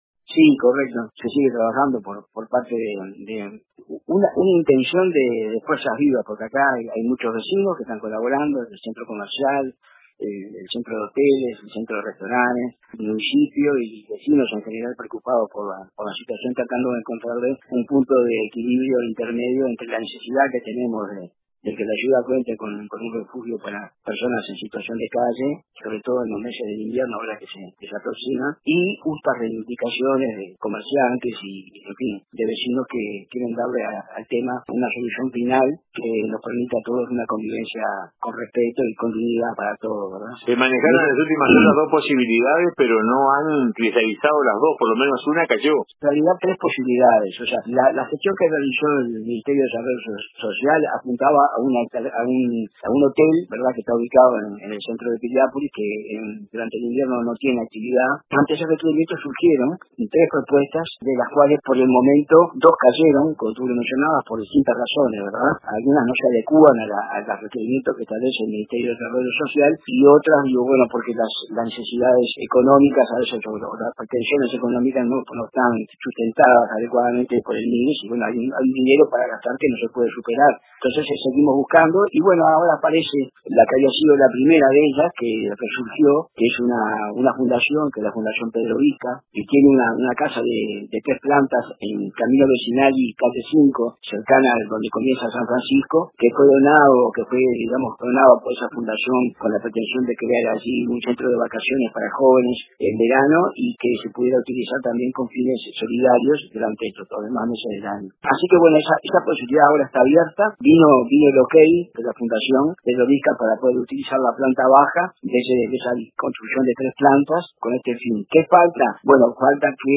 Carlos Fuentes, concejal del Municipio de Piriápolis y como tal activo participante de las gestiones, contó detalles para el informativo de RADIO RBC